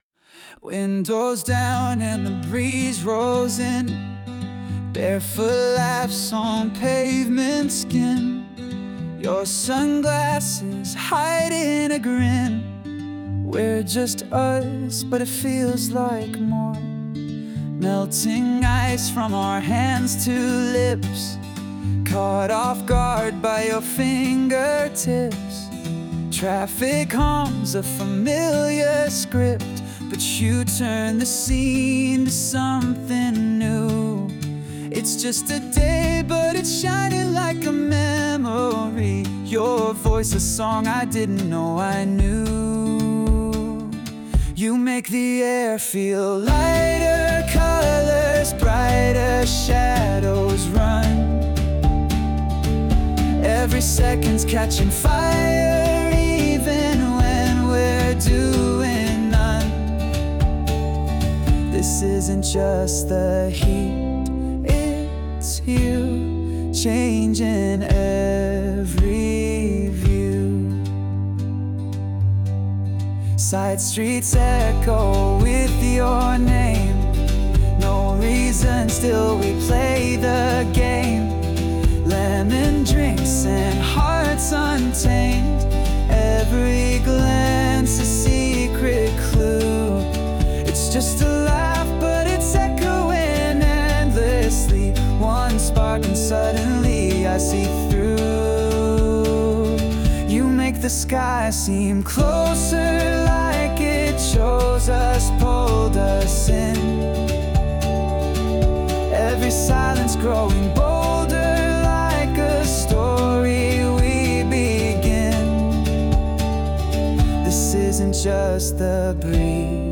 洋楽男性ボーカル著作権フリーBGM ボーカル
著作権フリーオリジナルBGMです。
男性ボーカル（洋楽・英語）曲です。
この楽曲は、爽やかな夏の風景と共に、恋の記憶や始まりを彩るような作品になっています。